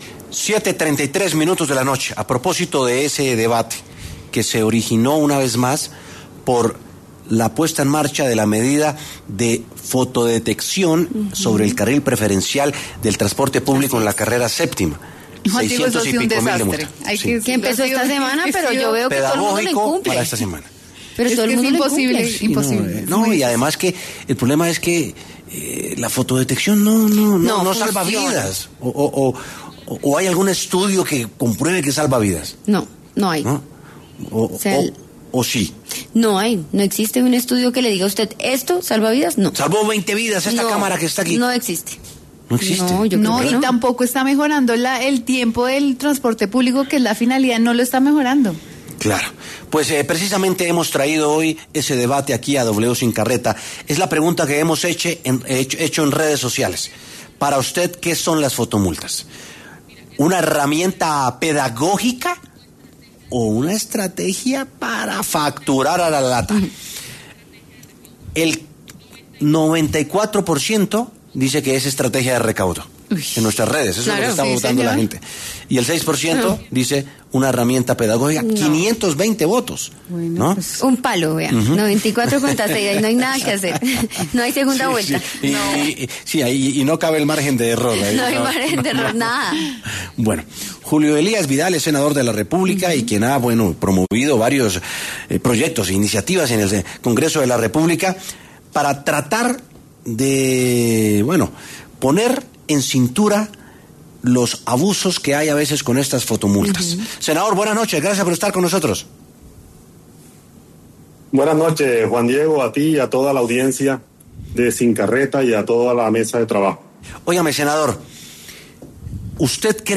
Debate: ¿son efectivas las fotomultas para evitar accidentes de tránsito?
En W Sin Carreta conversaron el senador del Partido de La U, Julio Elías Vidal y el concejal de Bogotá, Jesús David Araque, quienes explicaron su perspectiva.